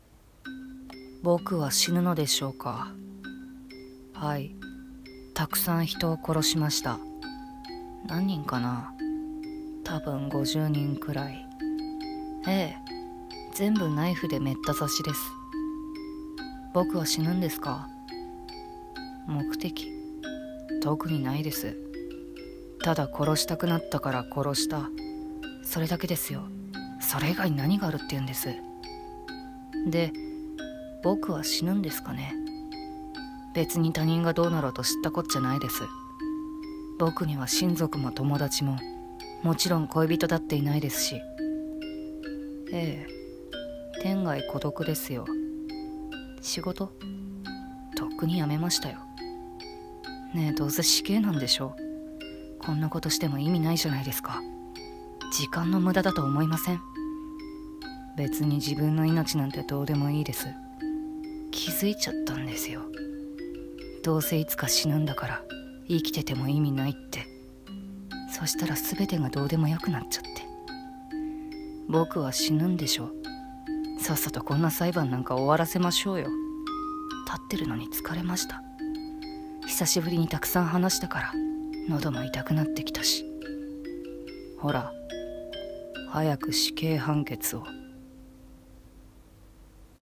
声劇「死刑裁判」